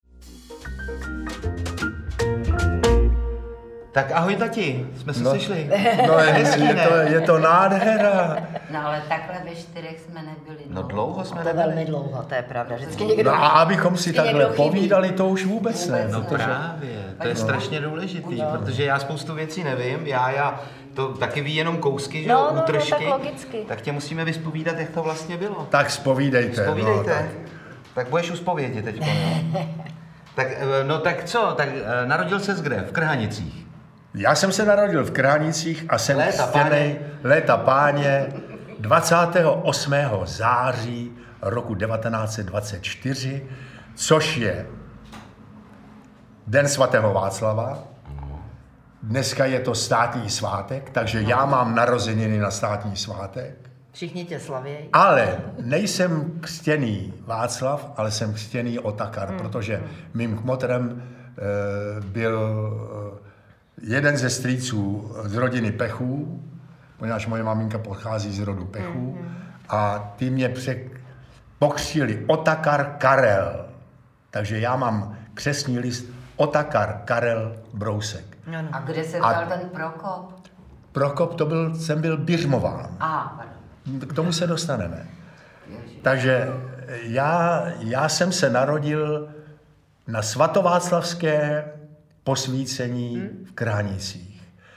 Všechno si pamatuju... audiokniha
Ukázka z knihy
Při poslechu zvučného hlasu pana Otakara Brouska budete se zatajeným dechem žasnout, kolik známých osobností za svůj dlouhý život potkal a co veselých historek zažil.
• InterpretOtakar Brousek st.